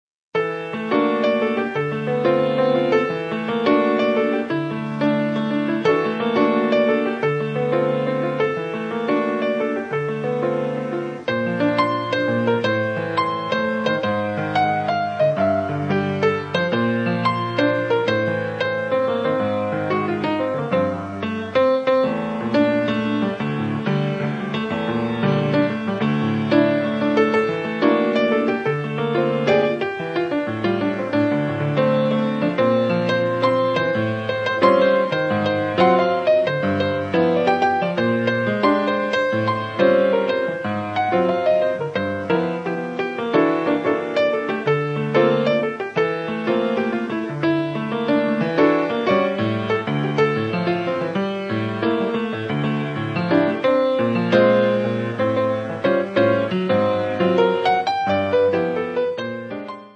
pianoforte
E' un disegno, scarno, essenziale e liberatorio.